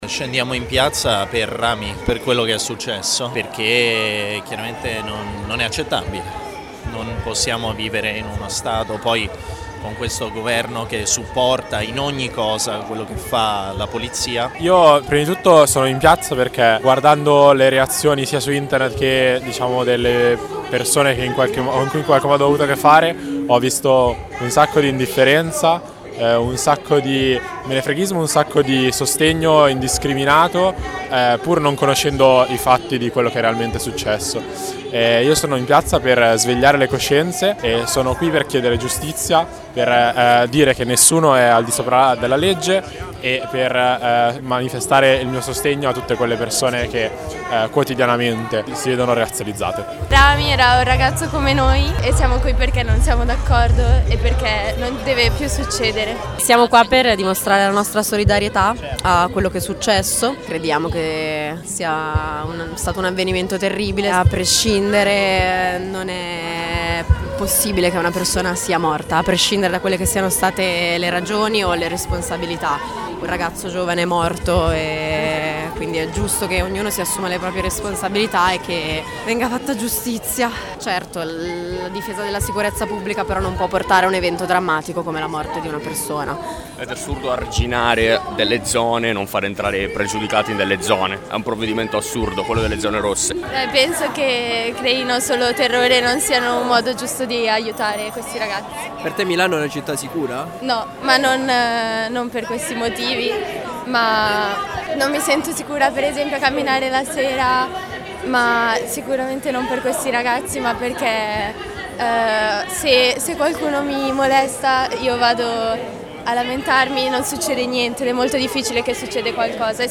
Noi ci siamo stati e abbiamo raccolto queste voci.